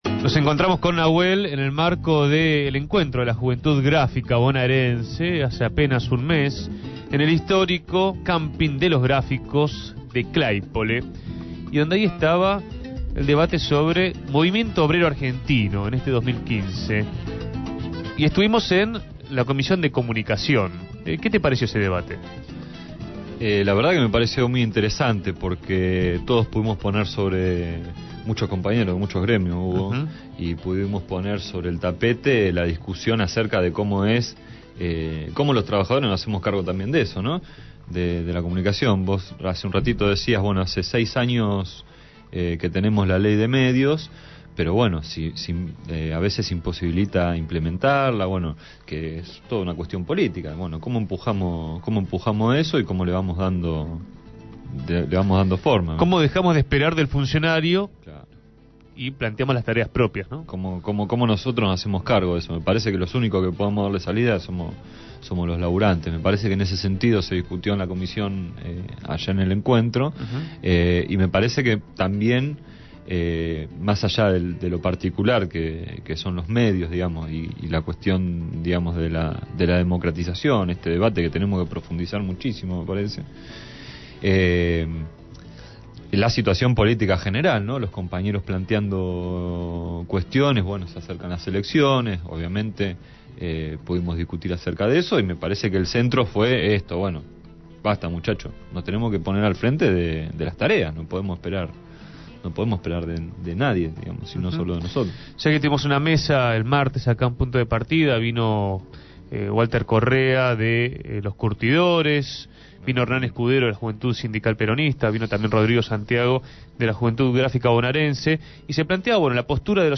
Entrevistado por Punto de Partida